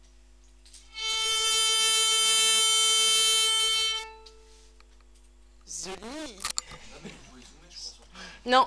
Ecouter la première note la 4 jouée par les différents instruments.
le violon ,